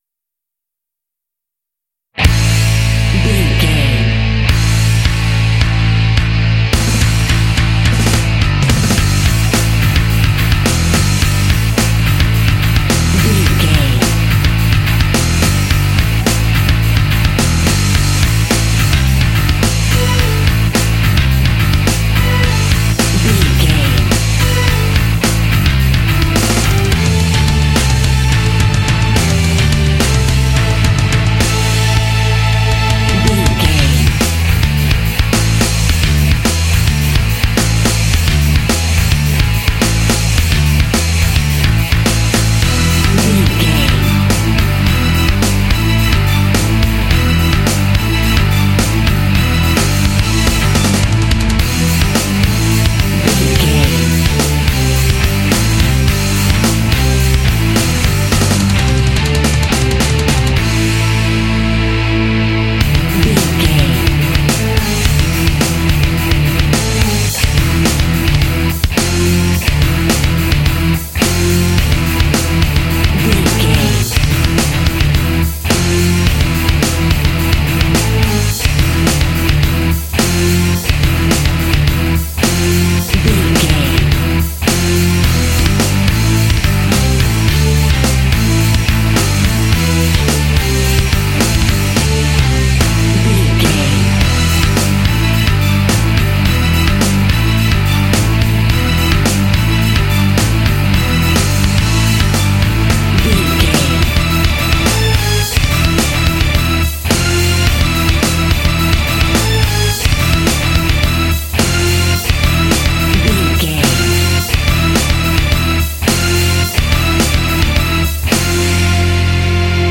Epic / Action
Aeolian/Minor
powerful
energetic
heavy
drums
bass guitar
electric guitar
strings
heavy metal
classic rock